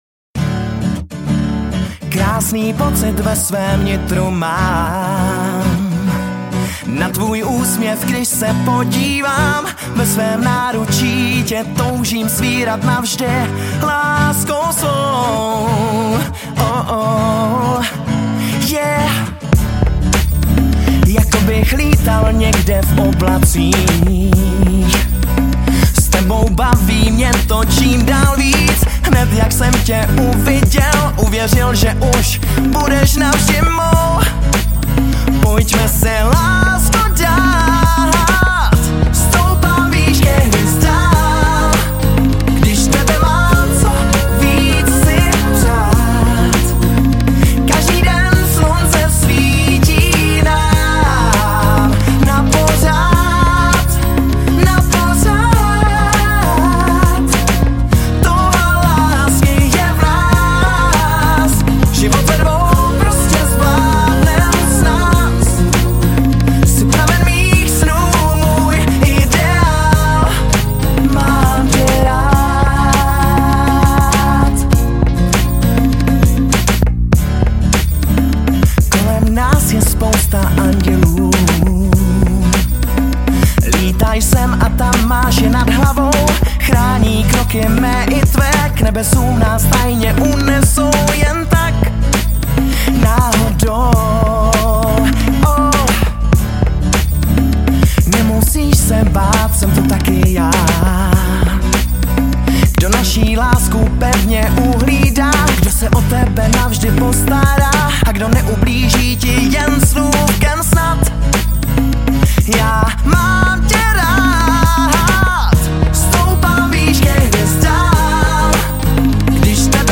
Žánr: Pop